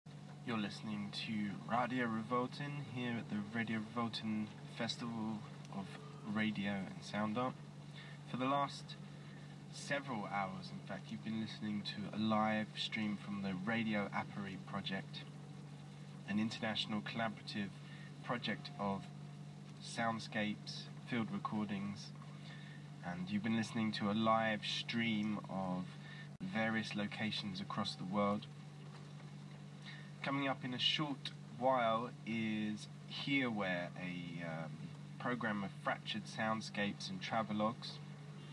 FM Mitschnitt 2